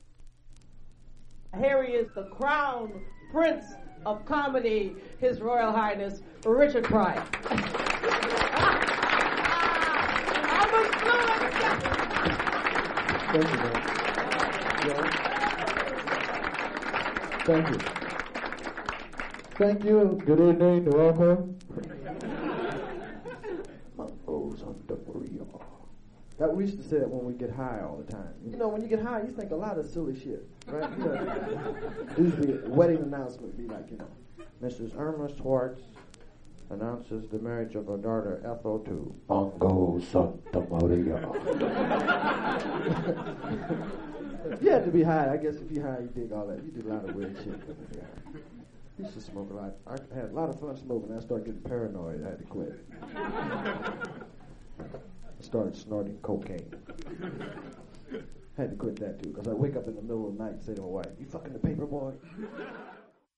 実際のレコードからのサンプル↓ 試聴はこちら： サンプル≪mp3≫